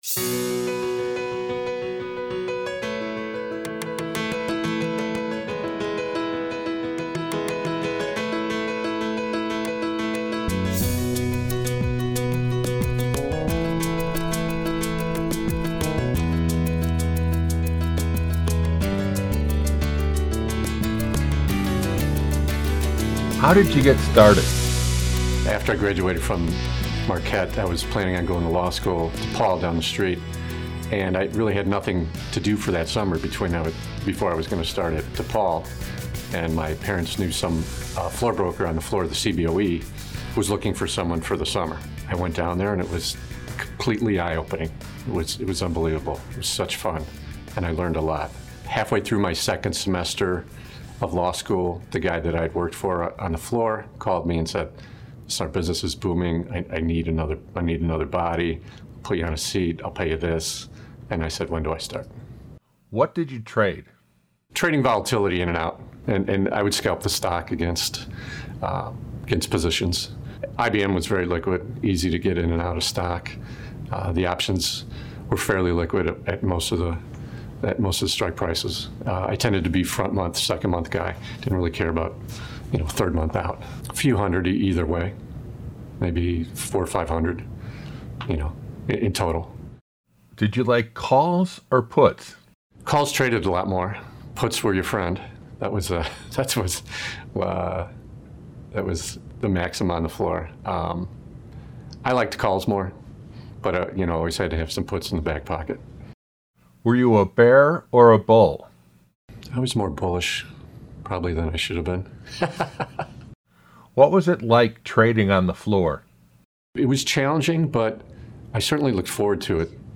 In this podcast version of the Open Outcry Traders History Project video interview